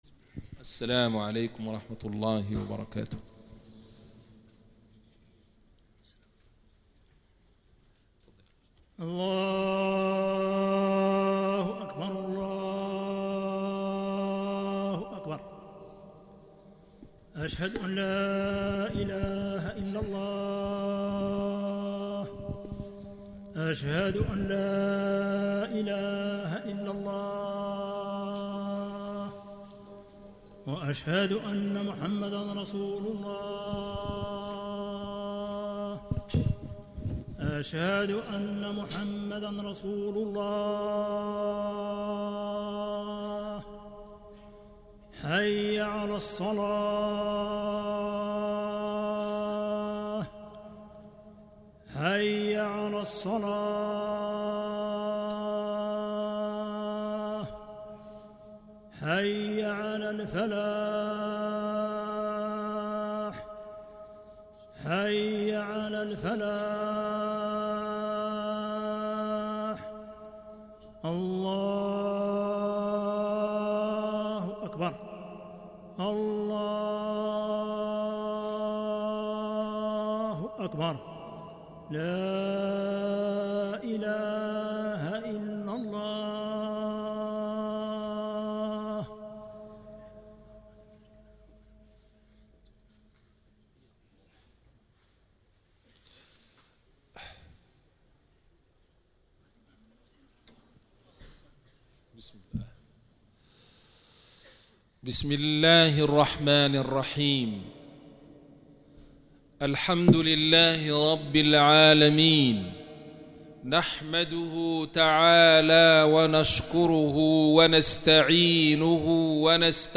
Les prêches du Vendredi